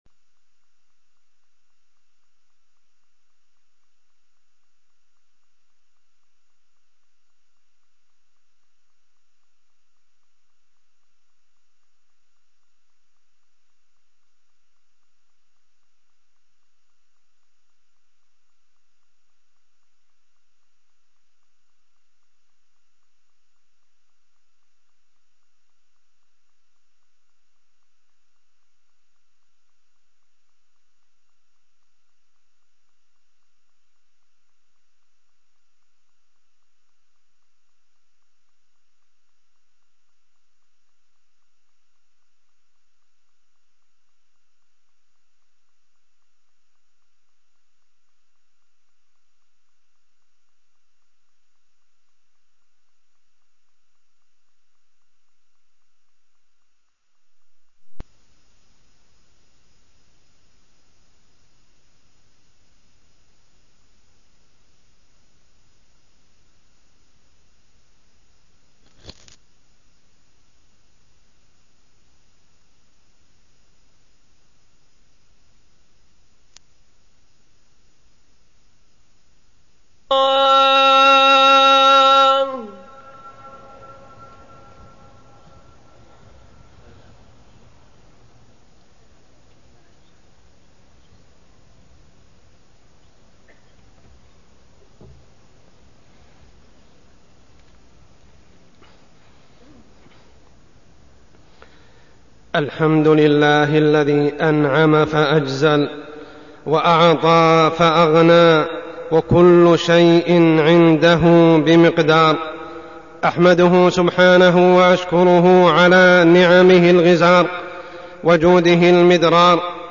تاريخ النشر ٢٤ محرم ١٤١٨ هـ المكان: المسجد الحرام الشيخ: عمر السبيل عمر السبيل المال الحرام